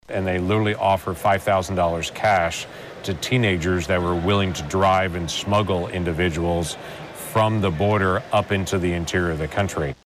CLICK HERE to listen to commentary from Senator James Lankford.